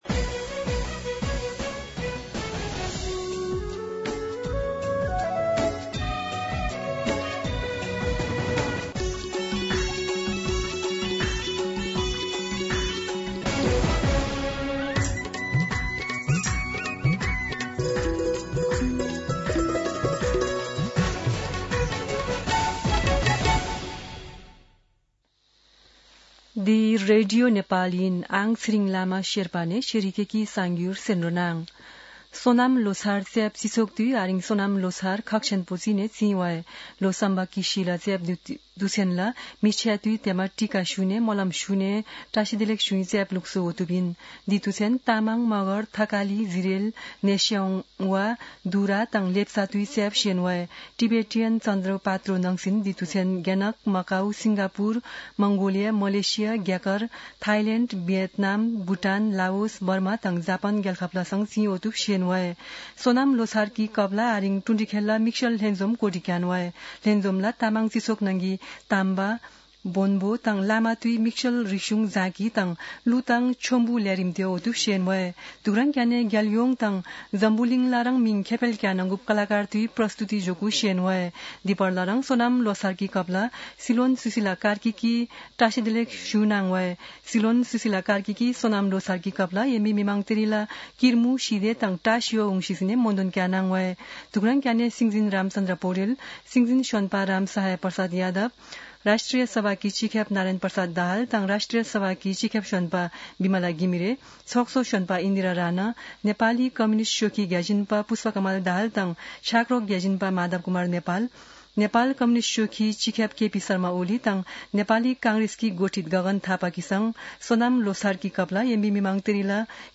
शेर्पा भाषाको समाचार : ५ माघ , २०८२
Sherpa-News-1-1.mp3